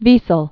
(vēsəl), Elie(zer) 1928-2016.